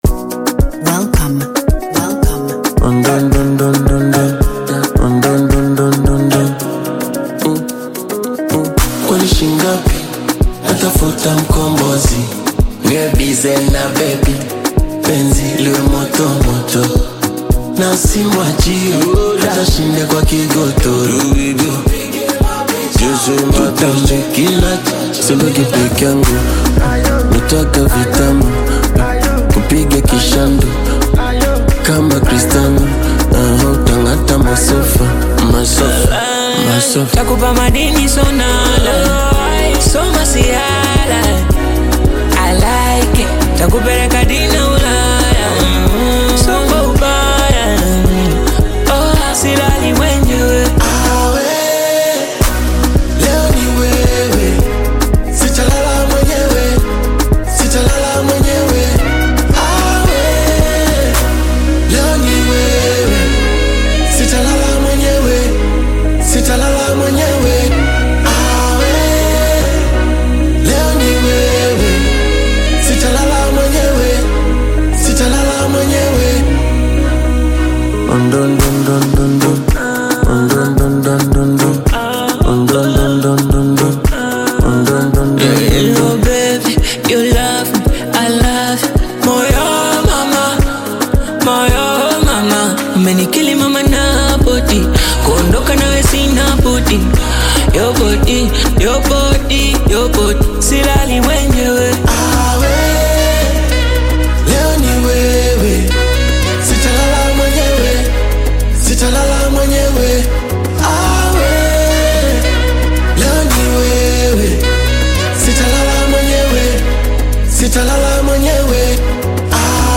Tanzanian bongo flava music group